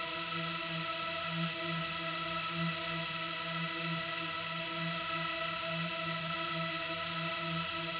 Index of /musicradar/stereo-toolkit-samples/Non Tempo Loops/Drones/Process05
STK_Drone4Proc05_E.wav